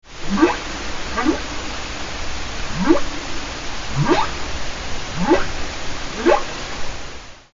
Whale:
HumpbackWhaleCall.mp3